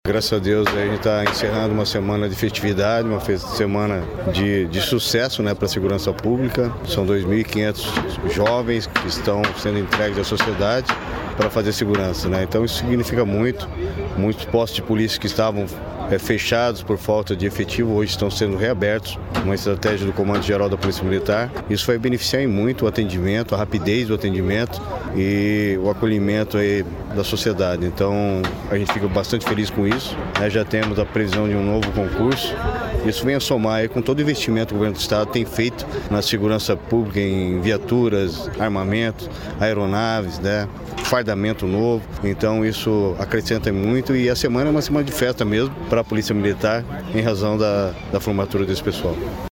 Sonora do secretário da Segurança Pública, Hudson Teixeira, sobre a formatura de 319 policiais militares em Cascavel